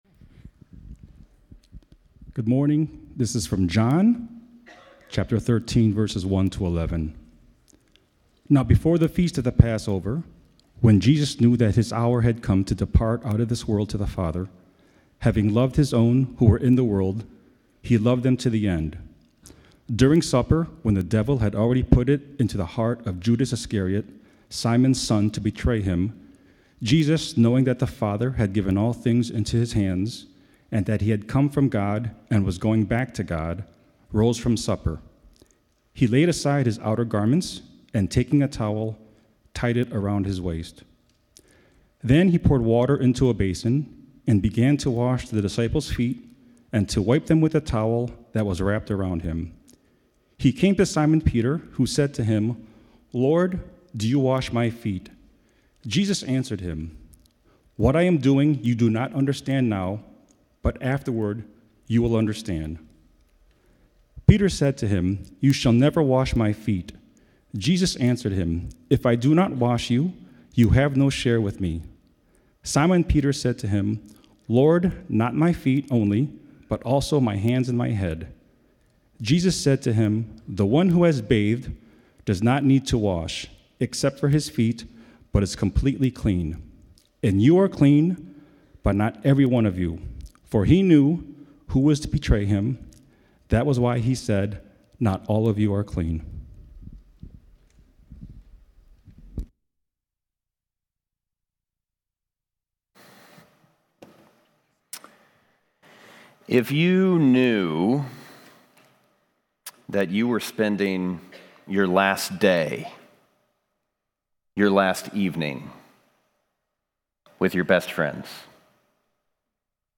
Join us each week as we dive deep into the Word of God and explore the life-transforming message of Jesus and the grace He offers us. In each episode, we bring you the dynamic and inspiring Sunday messages delivered by our passionate and knowledgeable pastors.